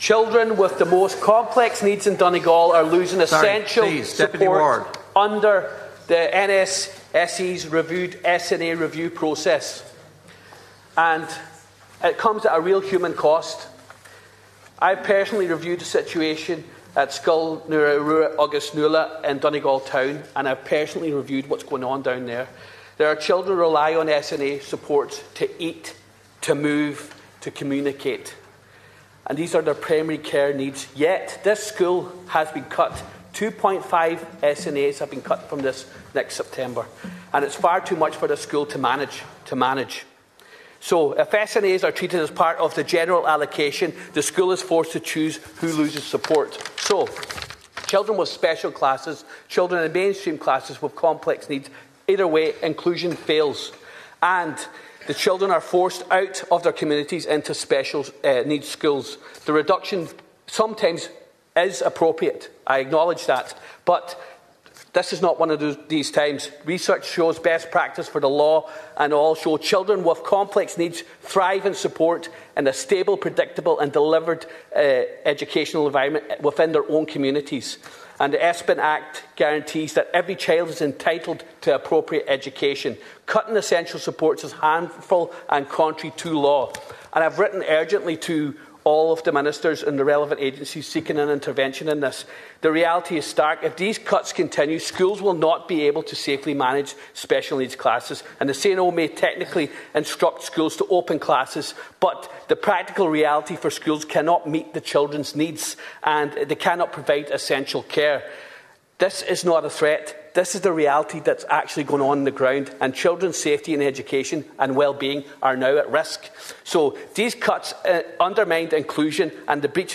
Government pauses SNA review as Ward raises concerns on the floor of Dail Eireann
Michael Martin was responding to Donegal TD Charles Ward, who told the Dail under a previous model, SNA supports were allocated based on a school’s specific care needs.